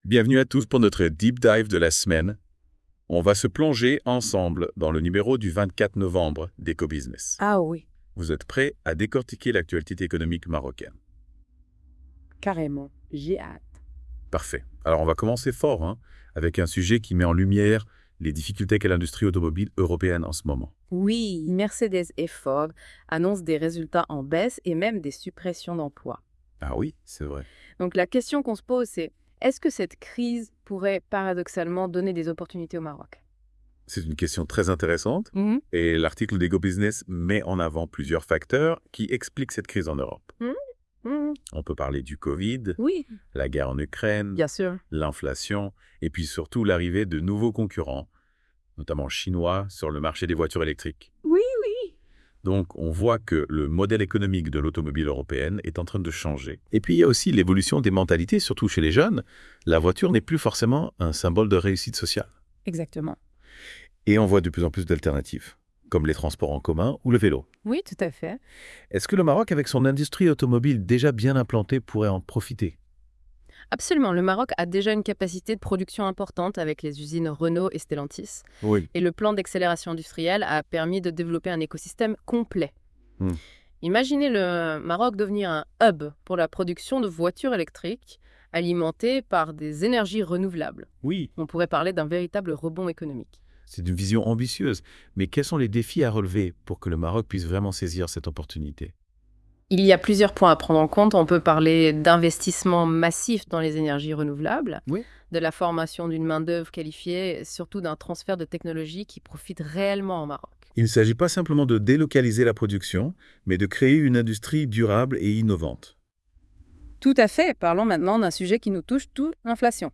Débat chroniqueurs R212 sur Hebdo ECO Busness.wav (33.01 Mo)